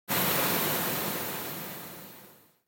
دانلود آهنگ آتشفشان 5 از افکت صوتی طبیعت و محیط
دانلود صدای آتشفشان 5 از ساعد نیوز با لینک مستقیم و کیفیت بالا
جلوه های صوتی